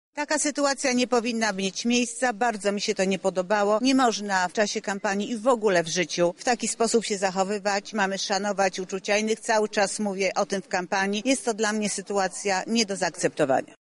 Władze partii powinny zareagować i mam nadzieję, że zareagują – mówi Małgorzata Kidawa – Błońska, kandydatka Koalicji Obywatelskiej na Prezesa Rady Ministrów.